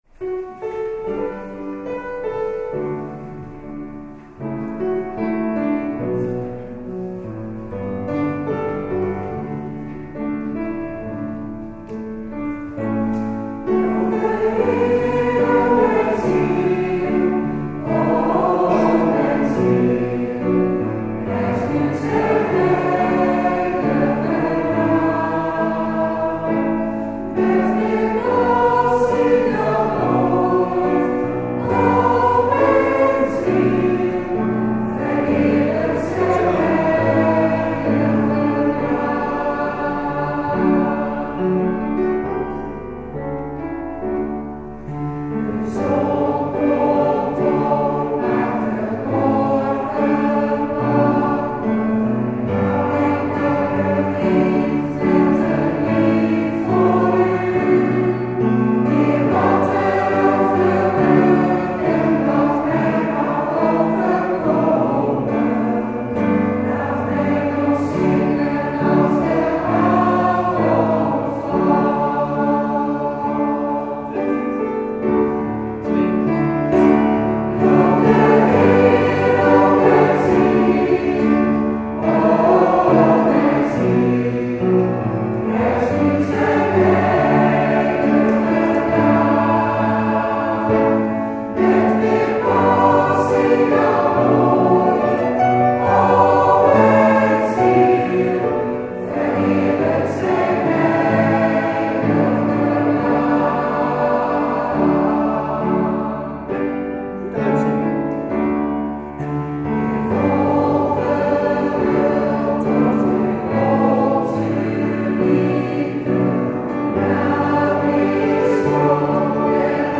Een koor zingt. En van sommige optredens en repetities hebben we een opname beschikbaar, en we willen deze muziek u en onszelf niet onthouden.
Repetitie April 2018, Herwijnen